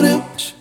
Hip Vcl Kord 1-D.wav